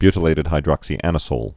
(bytl-ātĭd hī-drŏksē-ănĭ-sōl)